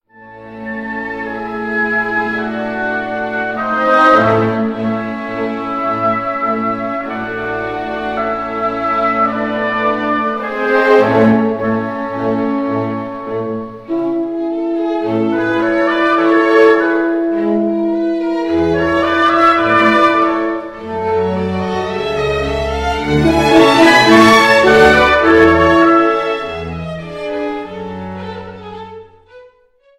Violoncello
Konzert für Violoncello und Orchester D-Dur - Allegro